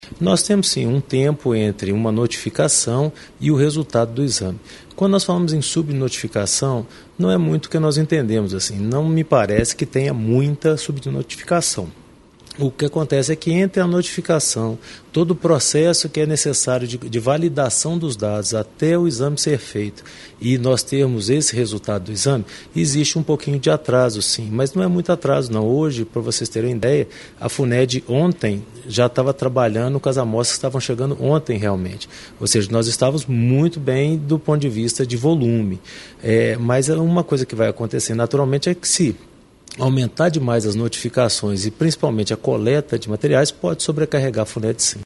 Ele explica a demora dos resultados e sobre as subnotificações.